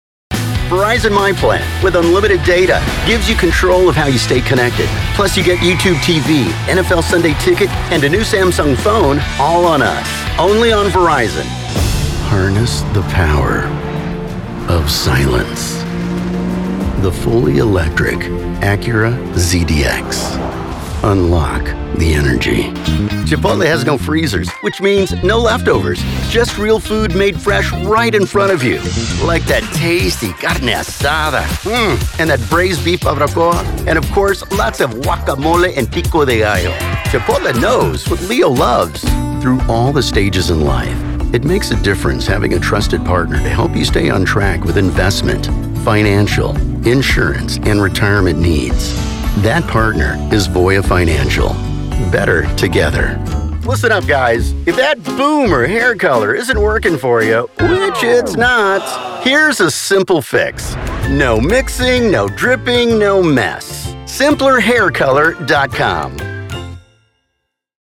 Full-Time, award-winning, bilingual voice actor with a pro studio.
Commercial Demo - English
Southern, Hispanic, Mexican, Latino, American Standard English
Middle Aged